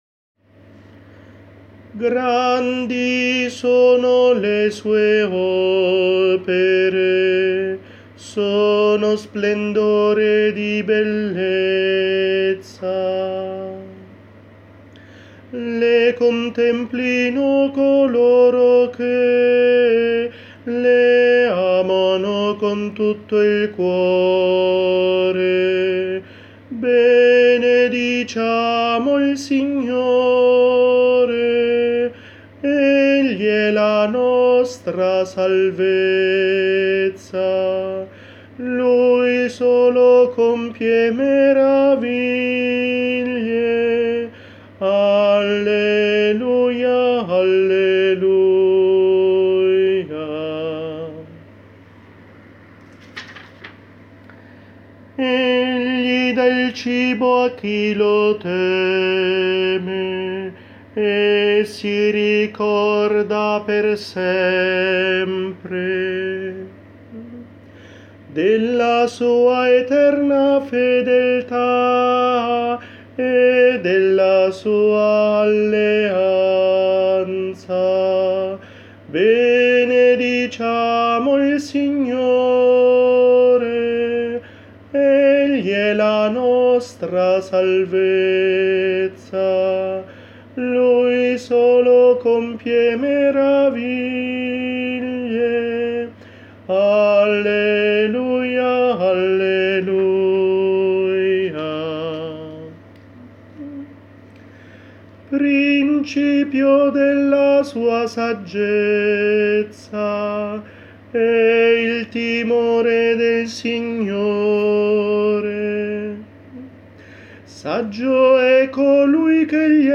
Parti Cantate
In questa sezione sono raccolte le parti cantate, delle diverse sezioni, in riferimento ad alcuni dei brani del Repertorio Condiviso.